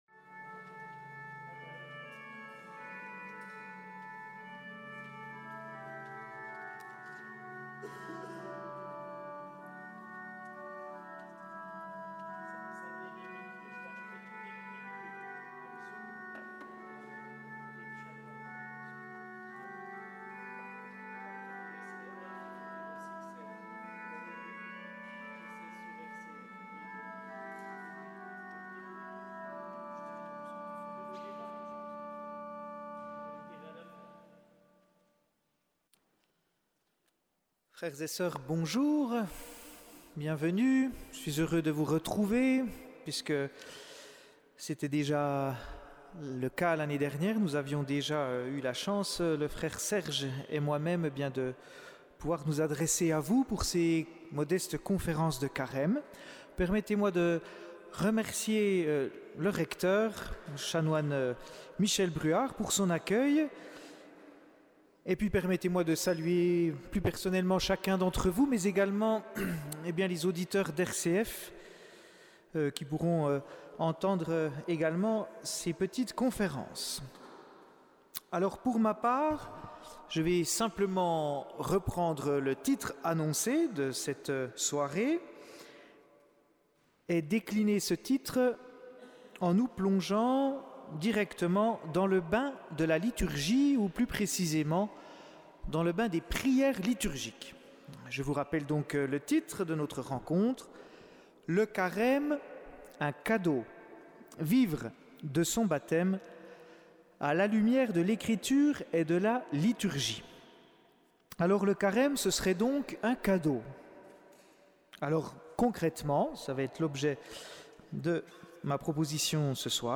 Conférence de Carême à la Cathédrale - 1 mars 2020
Conférences à deux voix